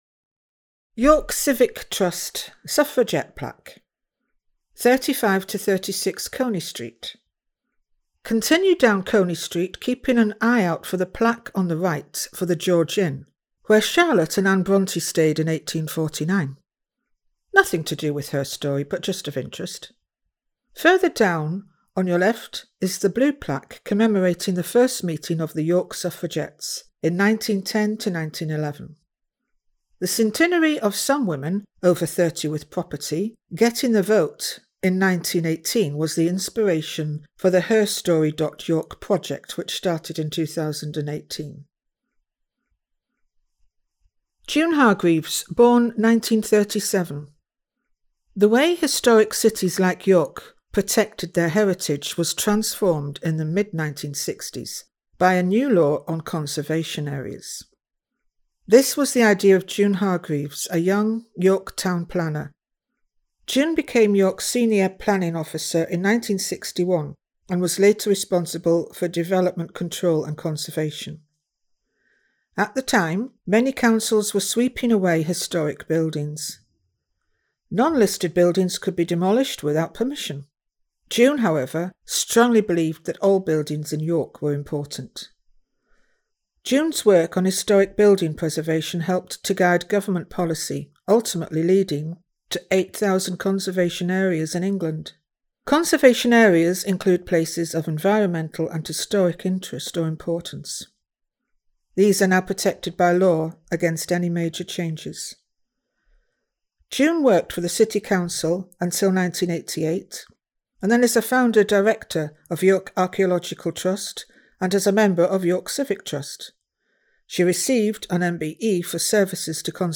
The tour itself, is narrated by another inspirational women